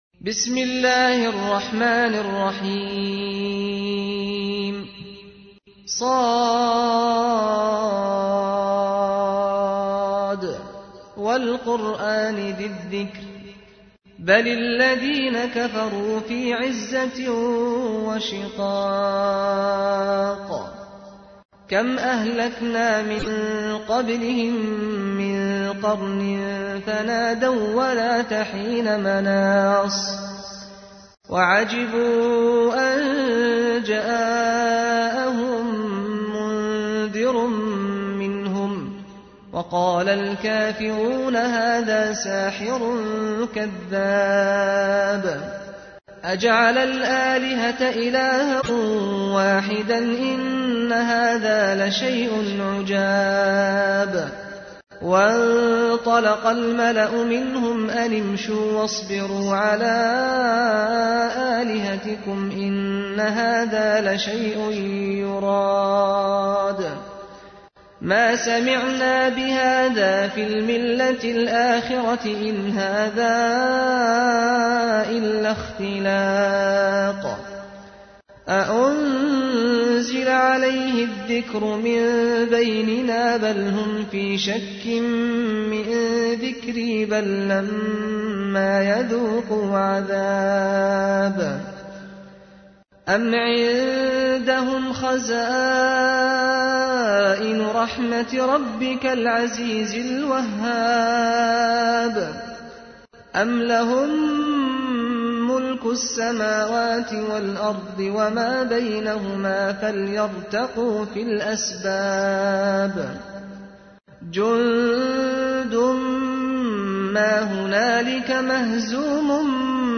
تحميل : 38. سورة ص / القارئ سعد الغامدي / القرآن الكريم / موقع يا حسين